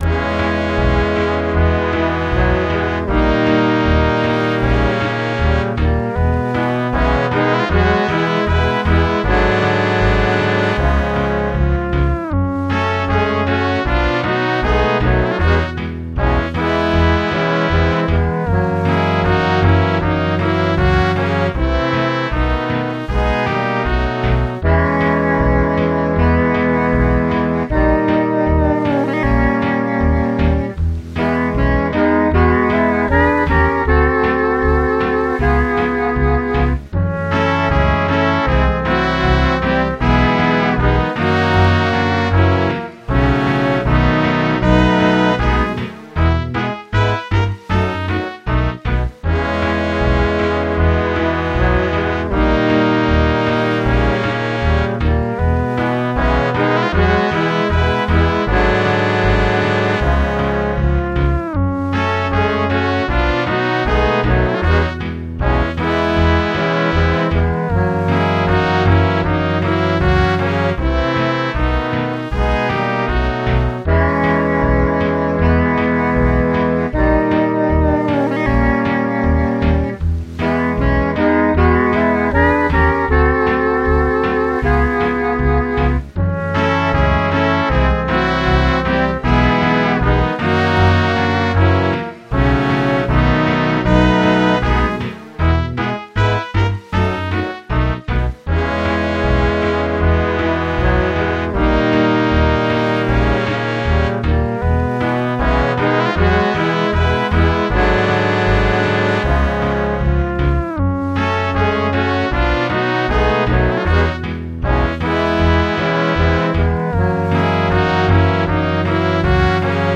Acoustic Bass
Trumpet / Alto Sax
Trombone
Piano 1
Strings
Tuba
Rain